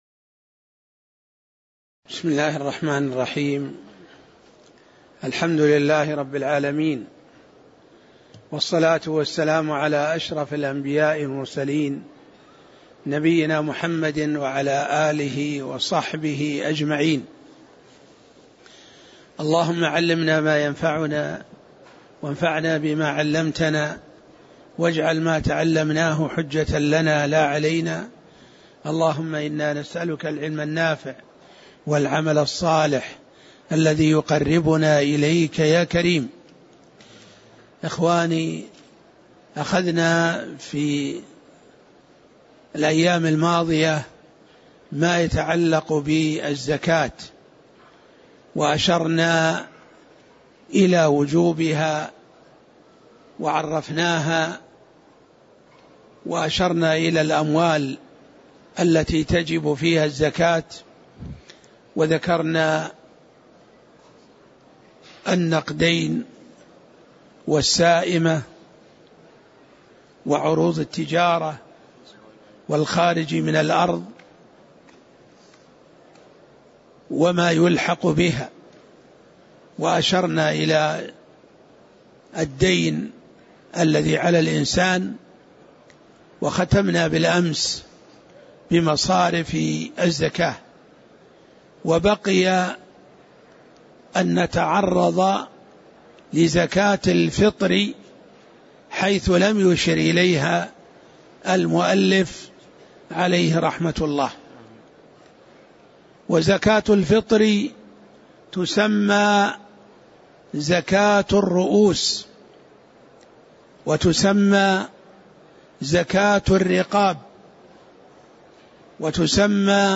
تاريخ النشر ٢٥ شعبان ١٤٣٦ هـ المكان: المسجد النبوي الشيخ